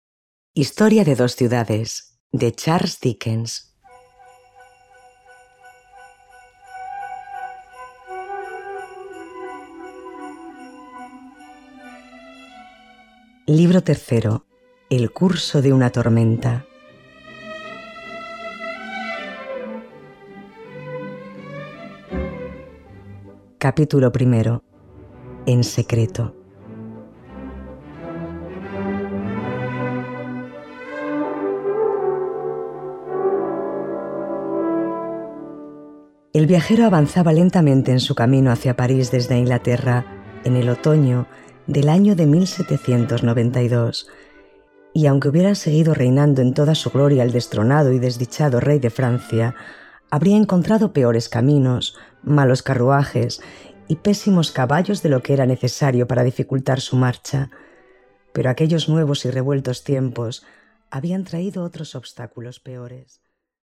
Música: Classiccat
Finalizamos la entrega de esta gran novela histórica con el libro tercero y último con que culmina esta cuidada adaptación sonora.